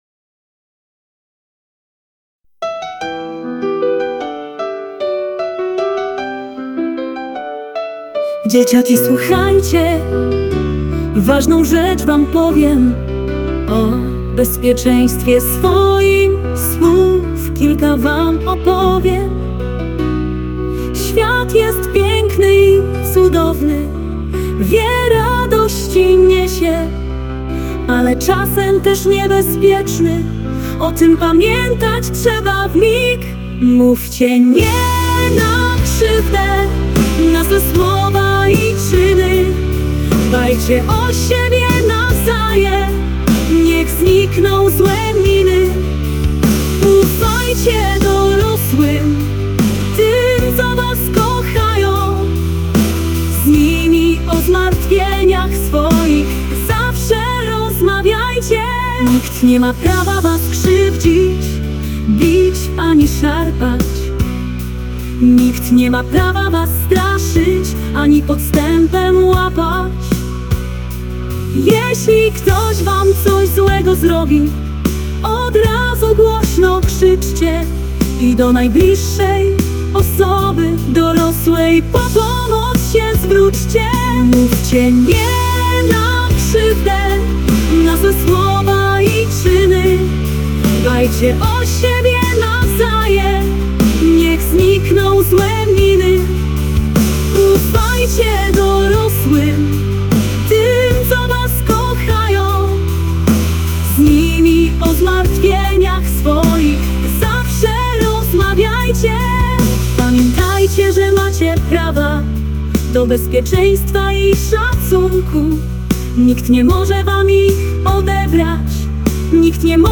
Piosenka – Bezpieczne dzieci